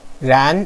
ran2.wav